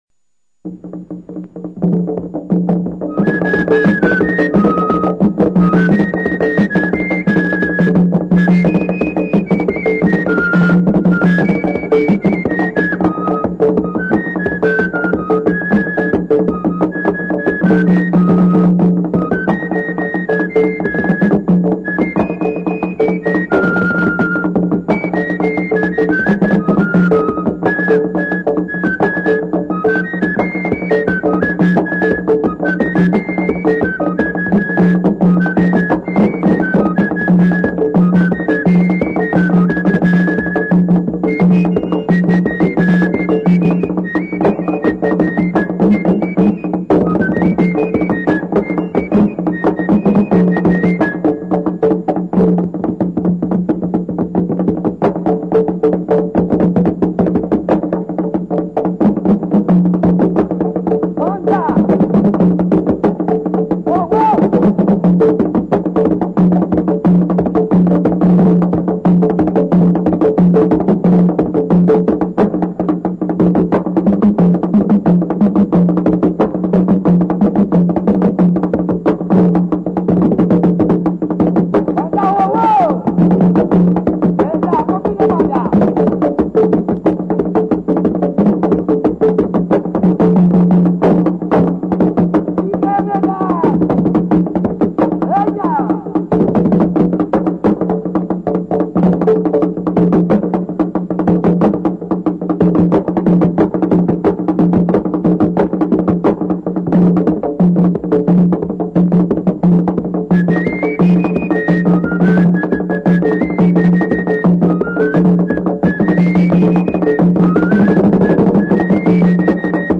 Contre danse flute et tambou 3
FLOKLORE HAITIEN
contre-danse-flute-et-tambou-3.mp3